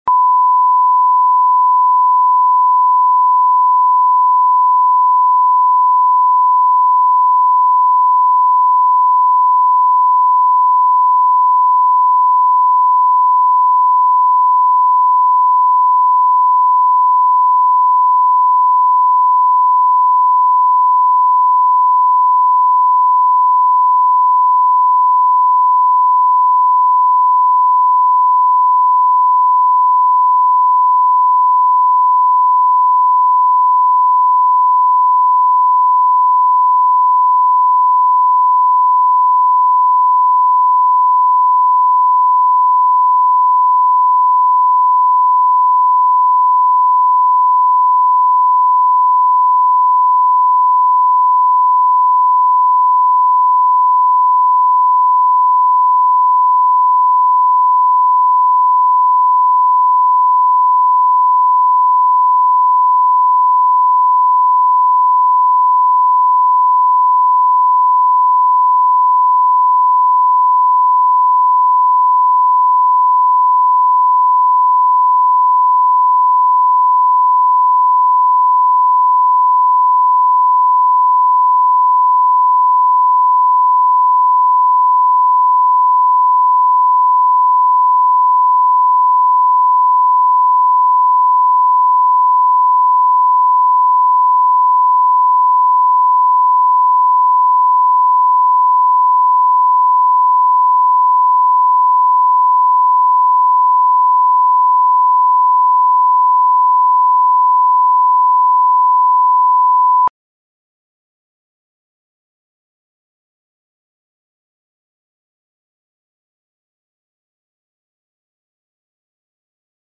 Аудиокнига День ангела | Библиотека аудиокниг
Прослушать и бесплатно скачать фрагмент аудиокниги